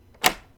action_lockboxclose.ogg